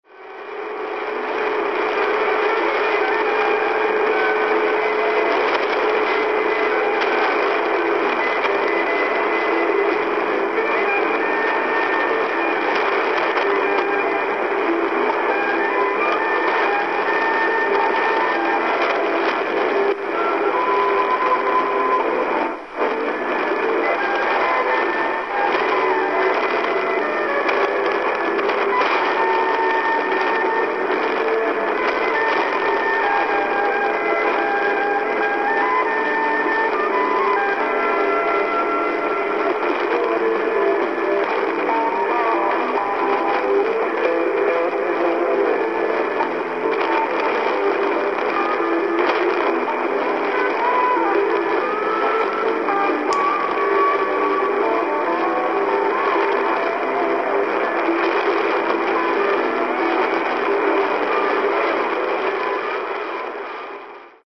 Tenho feito escutas em Ondas Médias muito interessantes apenas utilizando o DEGEN DE1103, sem o auxílio de antenas especiais.
1620kHz X-Band WDHP Ilhas Virgens EUA - Escuta em Guarulhos SP